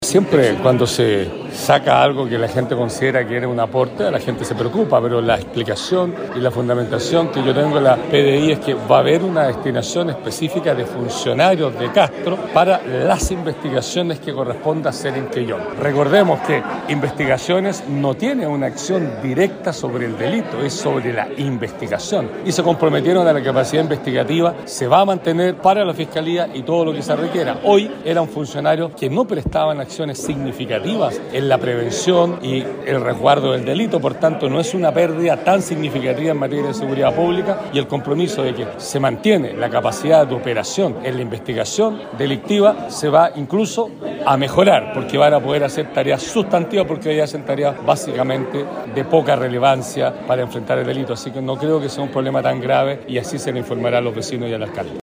Finalmente, el Gobernador Regional, Patricio Vallespín, dijo que la salida de la avanzada de la PDI no sería una pérdida tan significativa ya que su labor no sería de prevención, sino más bien de investigación de los delitos: